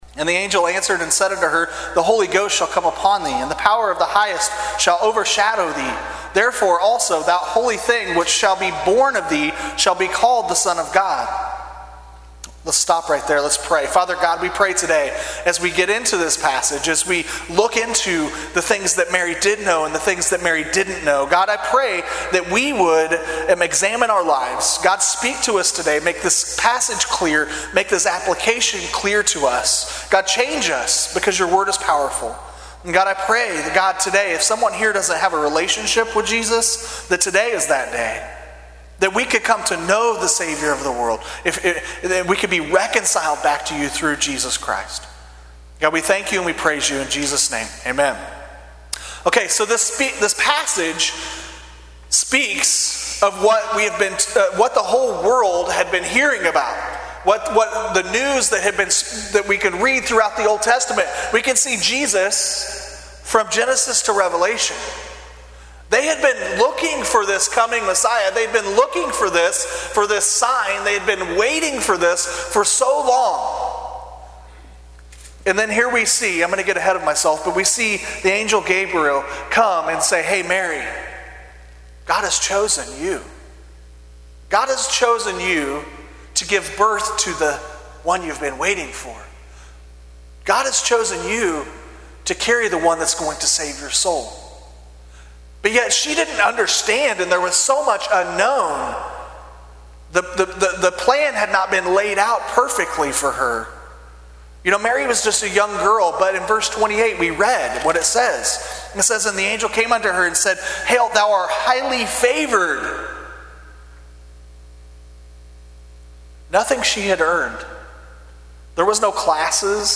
In this sermon on Luke 1:26-35